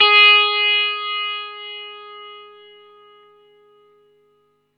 R12NOTE GS+2.wav